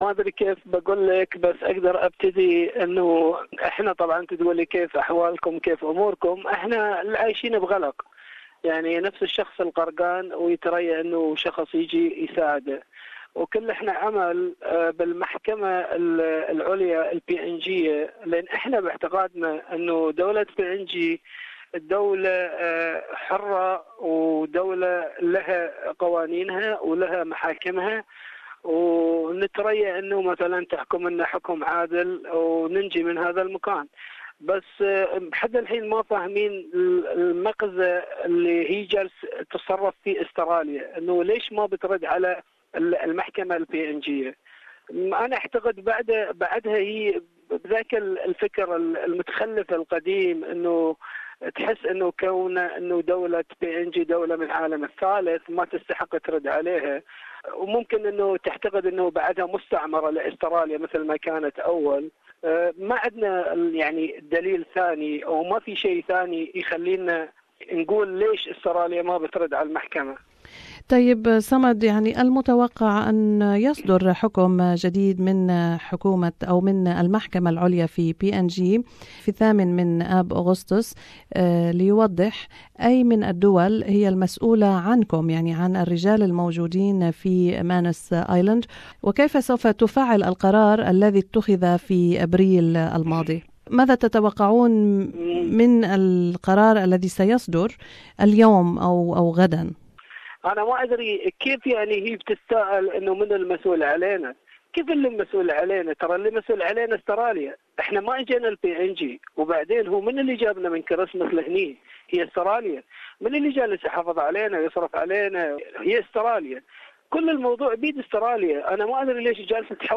في هذه المقابلة